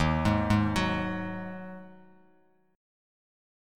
Ebmbb5 chord